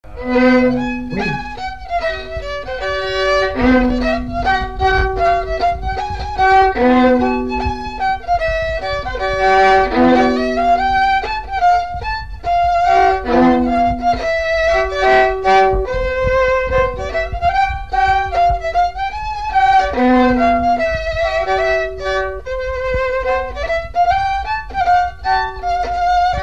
Note scottish
Résumé instrumental Fonction d'après l'analyste danse : scottish (autres)
Catégorie Pièce musicale inédite